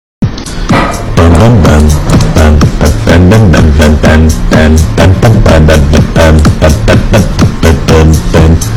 bem bem bem melastroi bass busted Meme Sound Effect
bem bem bem melastroi bass busted.mp3